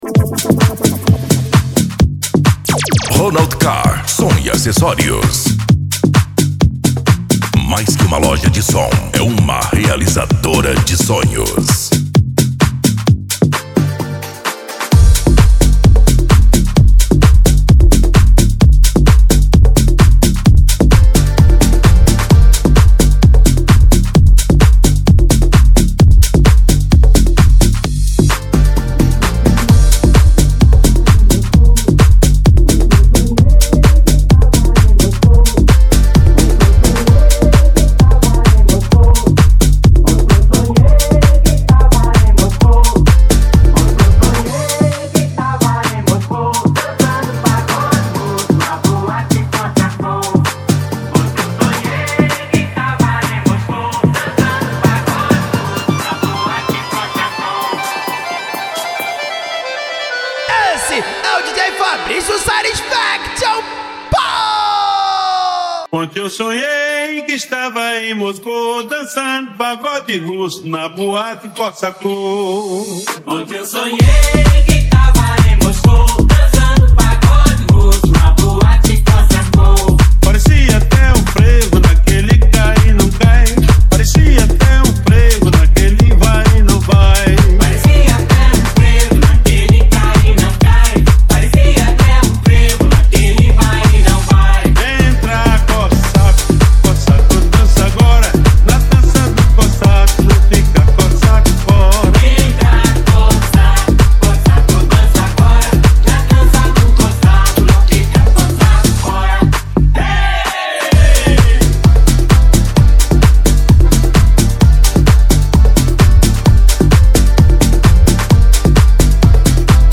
Bass
Deep House
Euro Dance